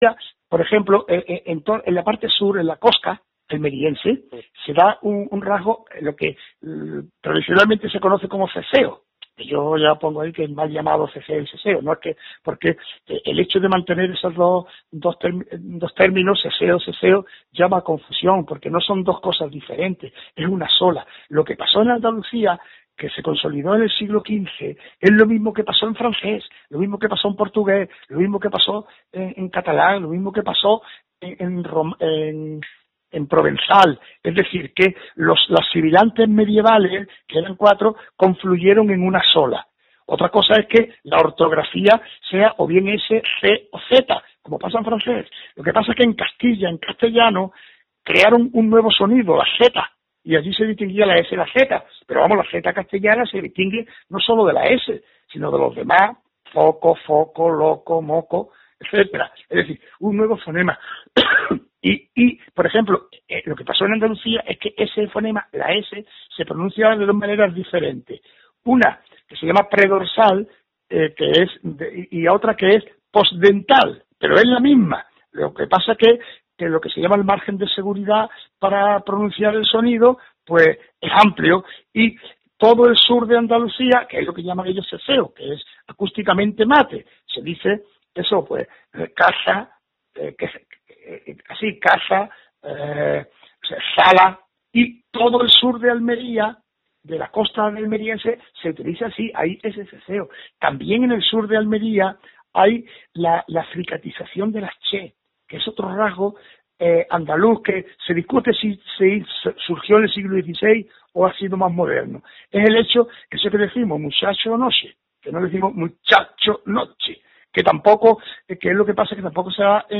Escuche las declaraciones: